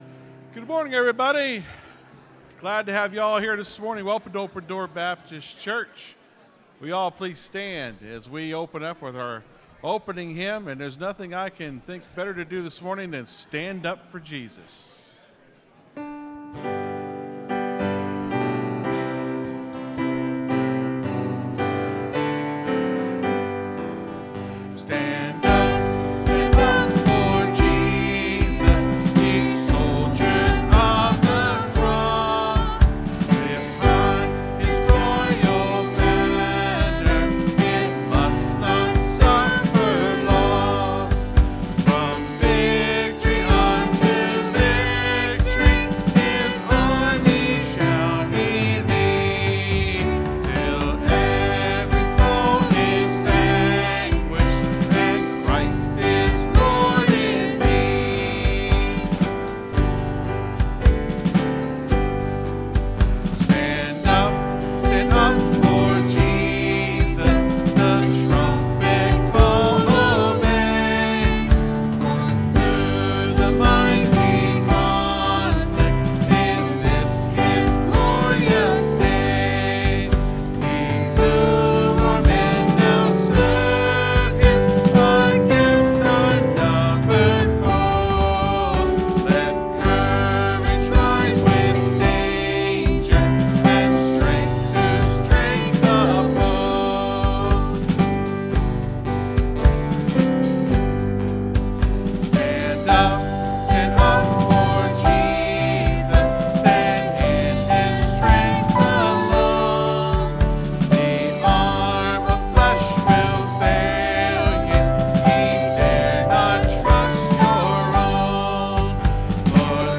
(Sermon starts at 26:50 in the recording).